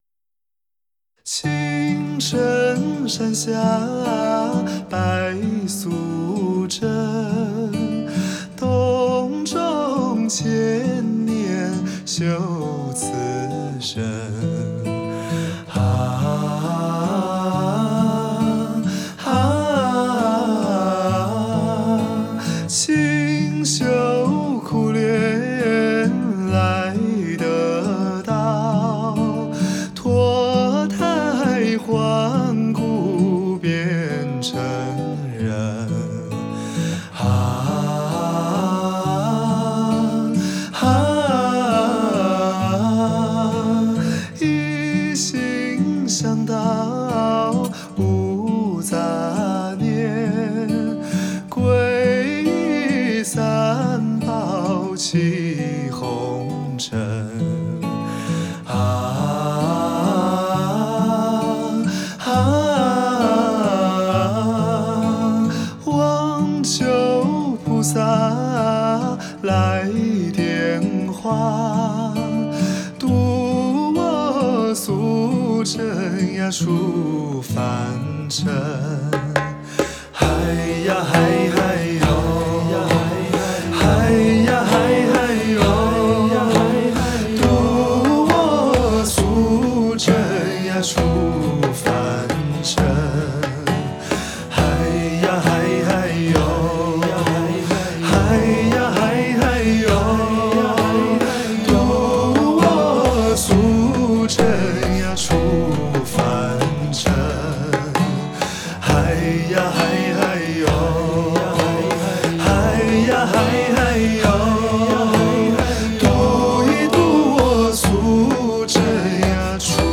Ps：在线试听为压缩音质节选